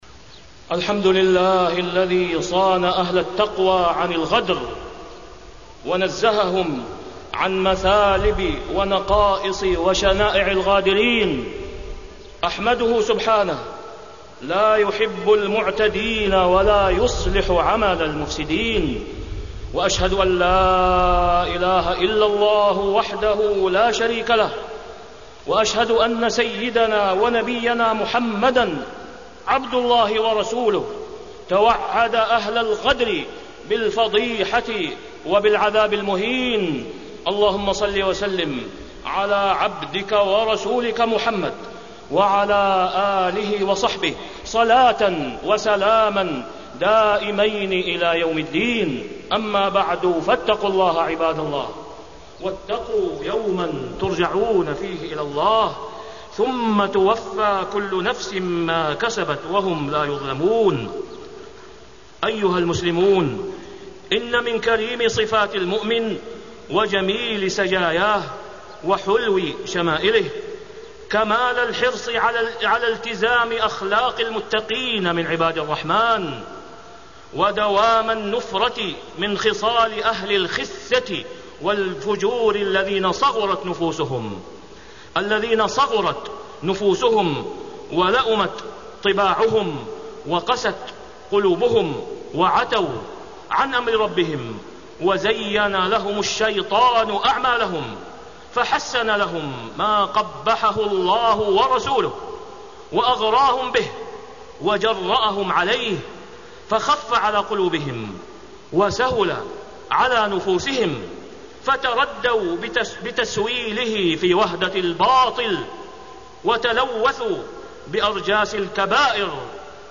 تاريخ النشر ١٩ صفر ١٤٢٨ هـ المكان: المسجد الحرام الشيخ: فضيلة الشيخ د. أسامة بن عبدالله خياط فضيلة الشيخ د. أسامة بن عبدالله خياط شناعة الغدر